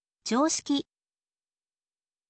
joushiki